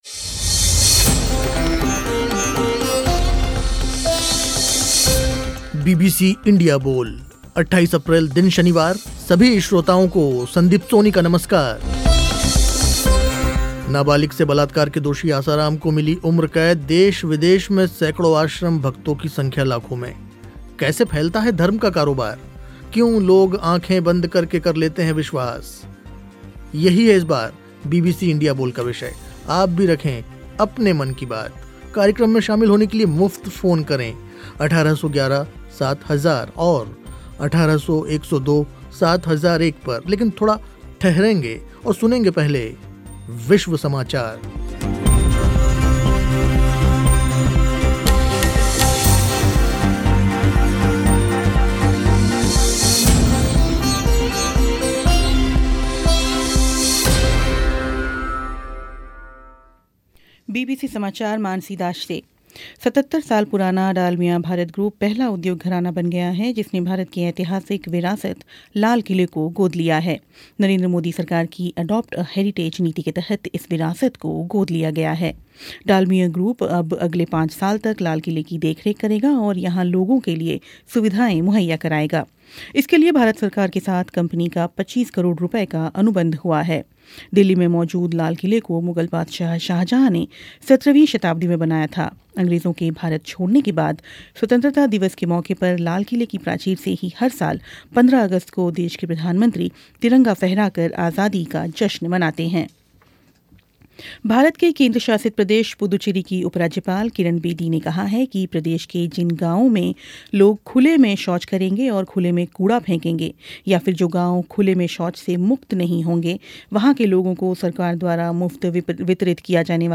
चर्चा हुई कैसे फैलता है धर्म का क़ारोबार और क्यों पाखंडी बाबाओं के झांसे में आ जाते हैं लोग